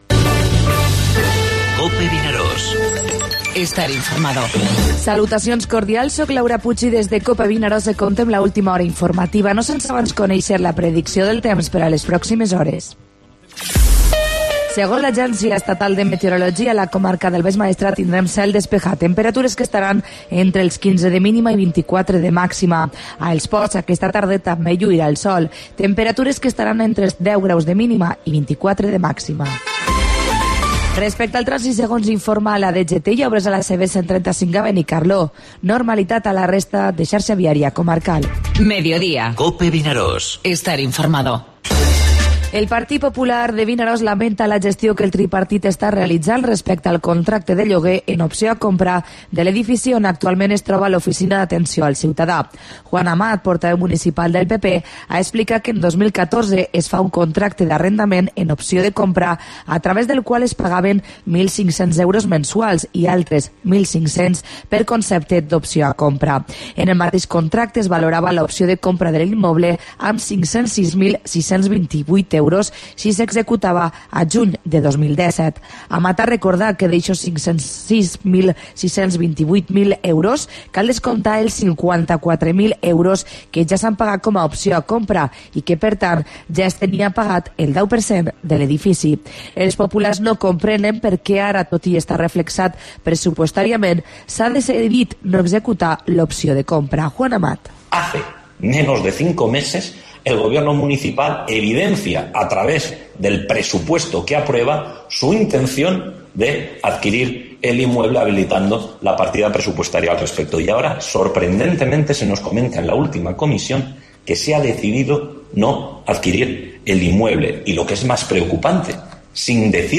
Informativo Mediodía COPE al Maestrat (16/5/17)